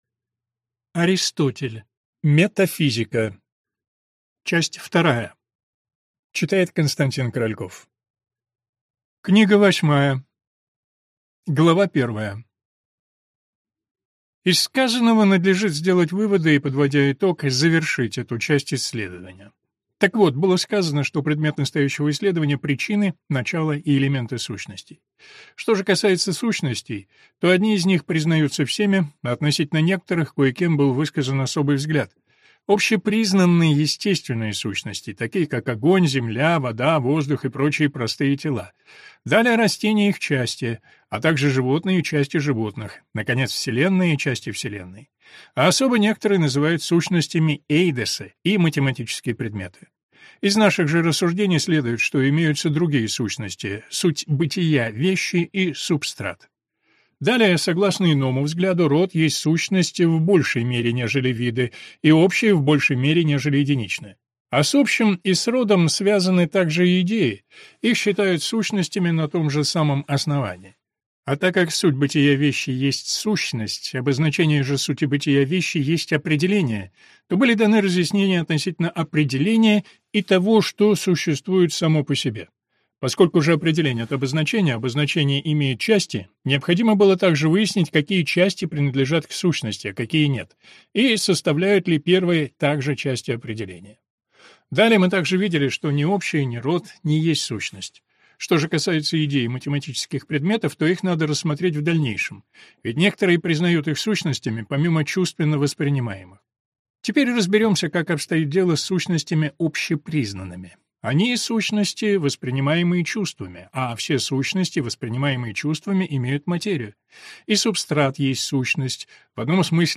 Аудиокнига Метафизика. Часть 2 | Библиотека аудиокниг